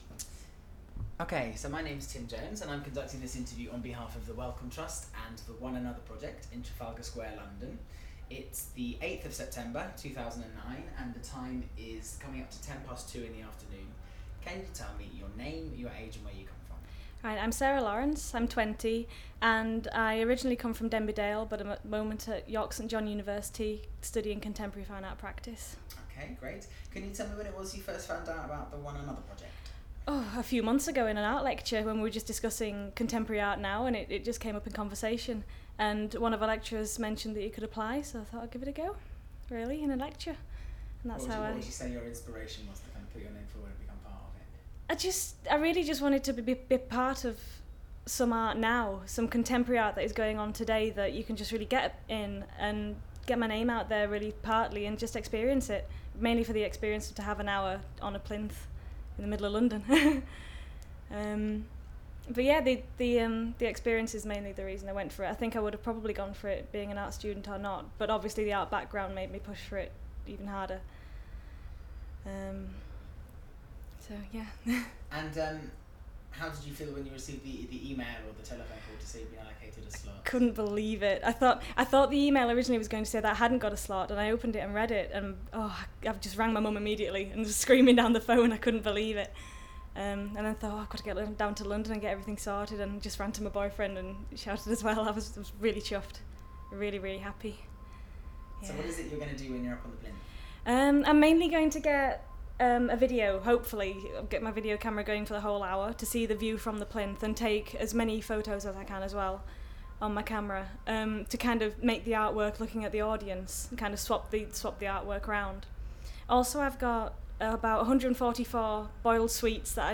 Audio file duration: 00:09:05 Format of original recording: wav 44.1 khz 16 bit ZOOM digital recorder.